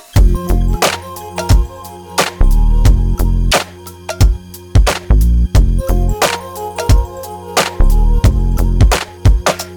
Is there a way to remove clap sound